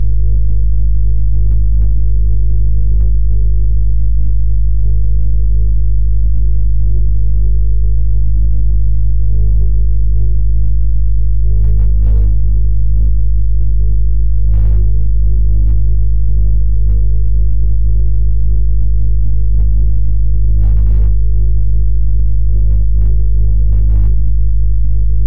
ambience ambient atmosphere bass creepy dark deep eerie sound effect free sound royalty free Nature